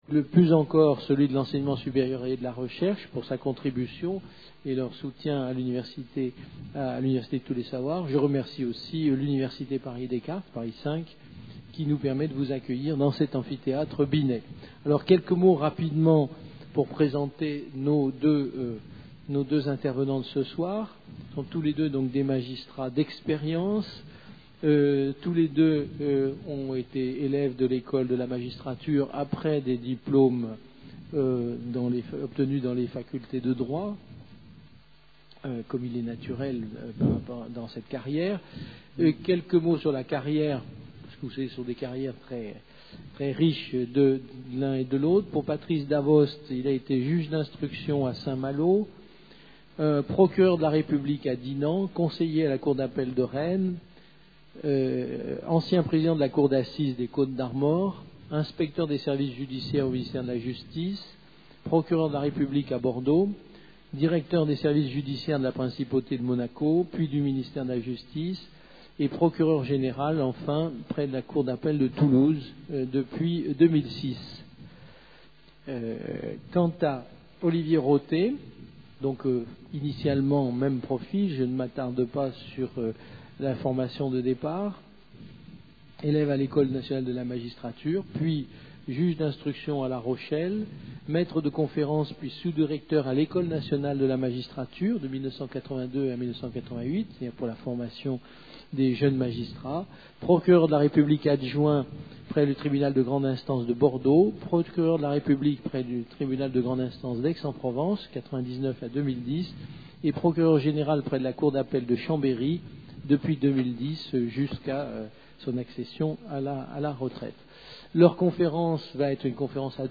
Une conférence du cycle "La violence aujourd'hui" Le juge et la violence avec Patrice Davost, magistrat et Olivier Rothé, procureur